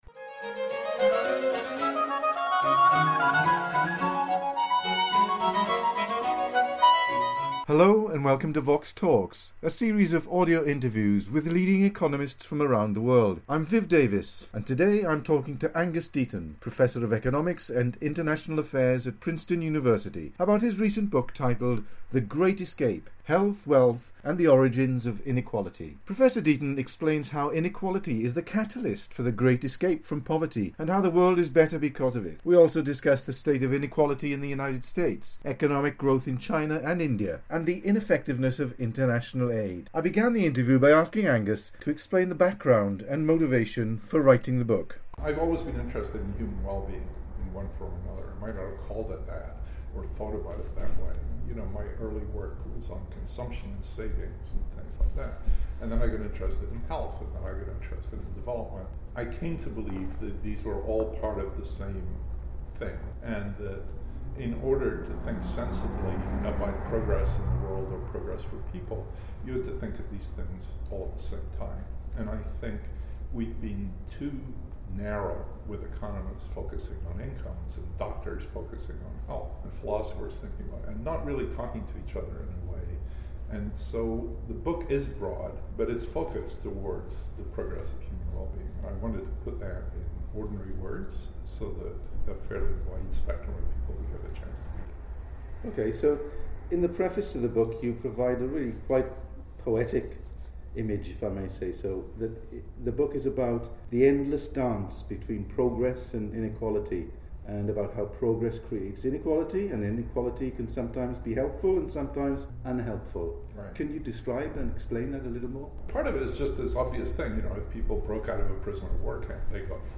Angus Deaton interviewed